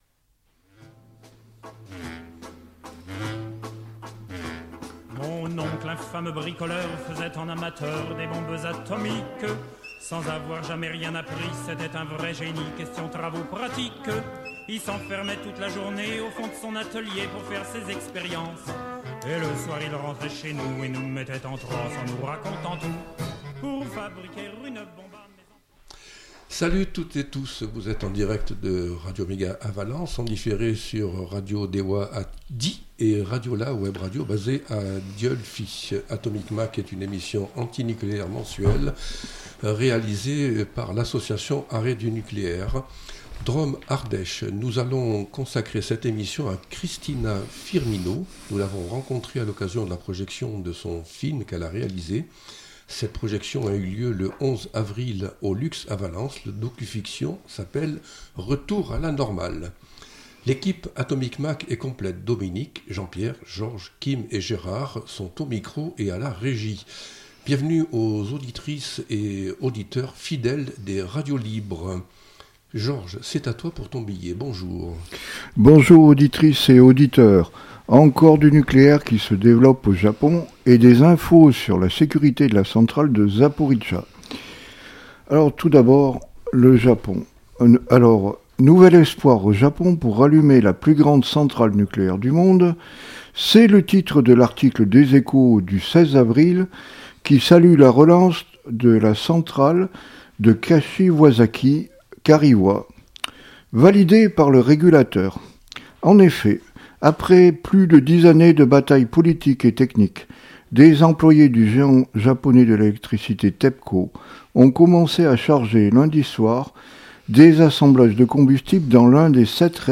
Atomic Mac, c’est l’émission radio mensuelle de l’association Arrêt du nucléaire Drôme-Ardèche. Du nucléaire civil au nucléaire militaire, de l’actualité locale à Cruas ou Tricastin et jusqu’à Bure, Fukushima ou ailleurs, tout y passe !